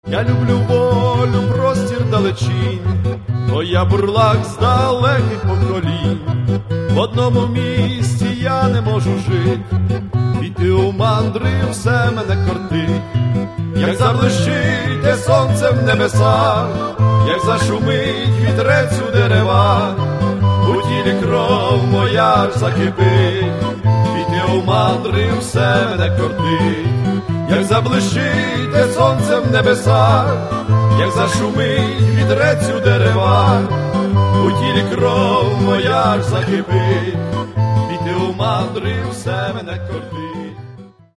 козацкие походные, кубанские и украинские народные песни
слова: пластова пісня